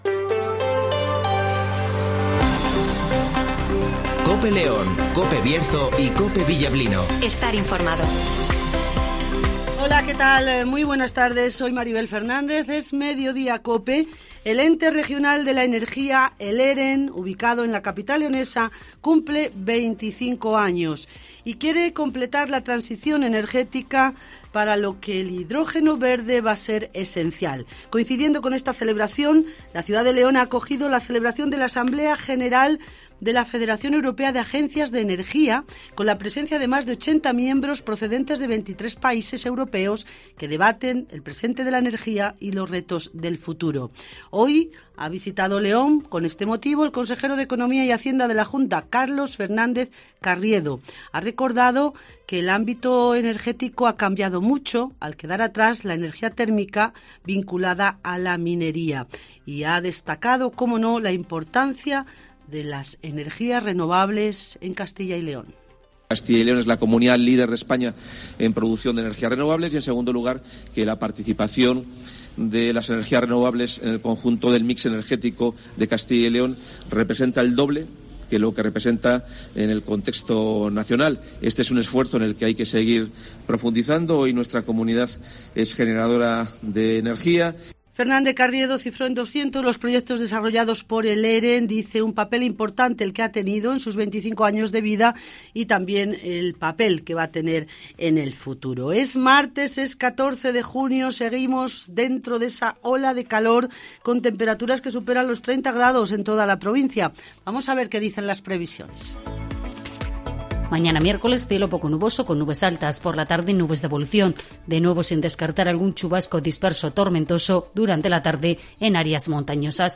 - Eduardo Morán ( Presidente de la Diputación de León )